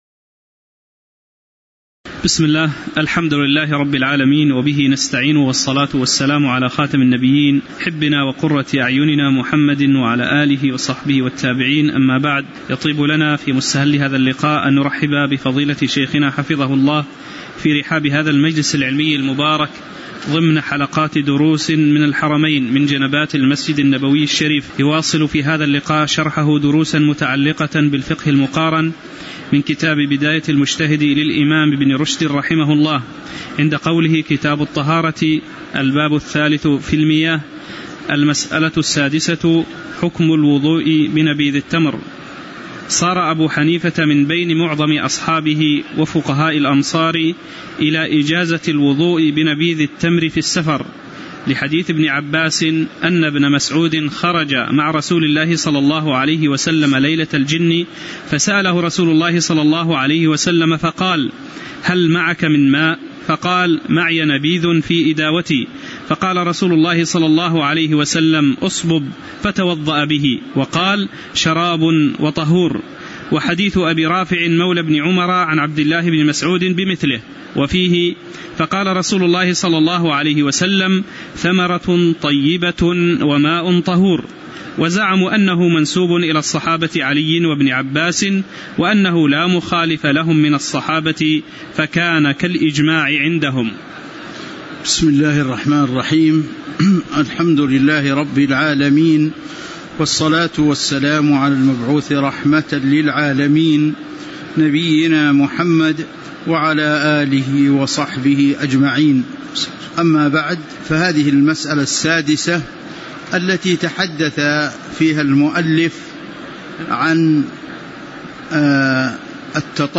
تاريخ النشر ١٣ صفر ١٤٤٠ هـ المكان: المسجد النبوي الشيخ